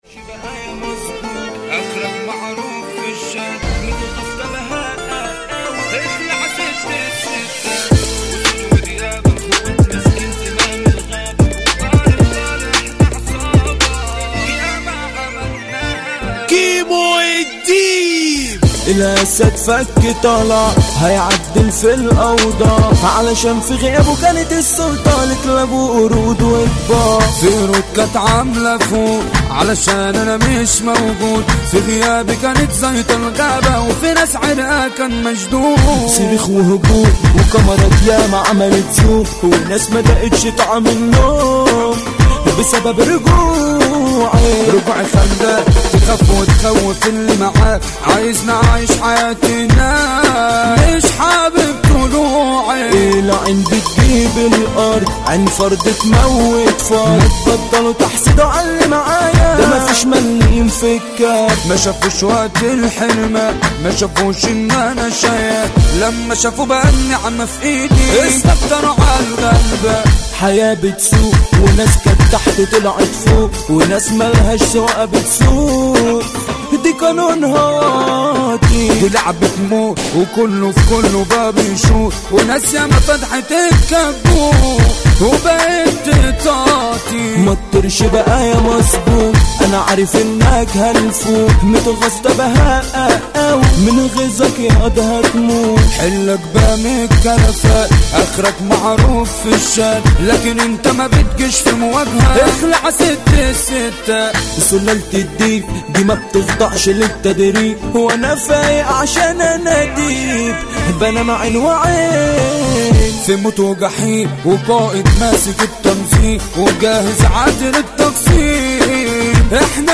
اغانى مهرجانات